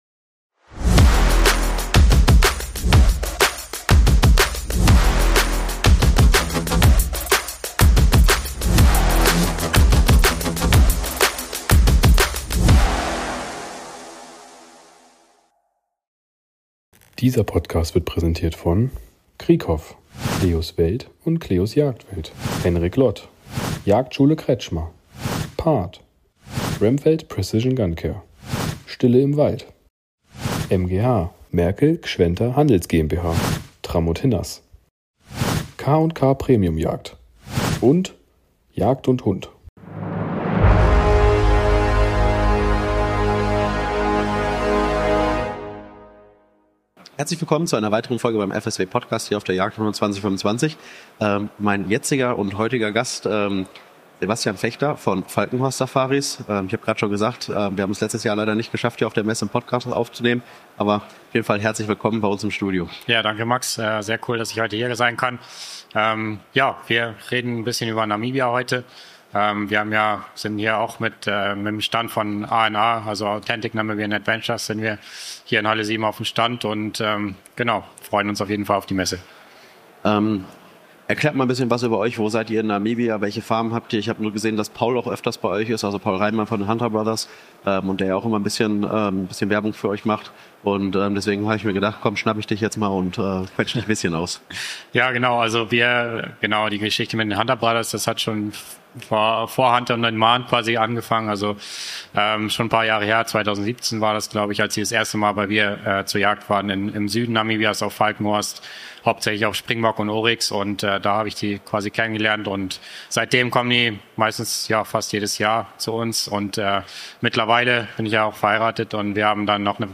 Wir sind live auf Europas größter Jagdmesse unterwegs und sprechen mit spannenden Gästen aus der Jagdszene. Ob Experten, Hersteller oder passionierte Jäger – in unseren Interviews gibt’s exklusive Einblicke, spannende Neuheiten und echte Insider-Talks rund um die Jagd.